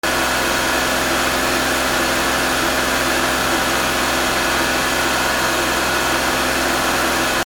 / M｜他分類 / L10 ｜電化製品・機械
脱水機
『ガー』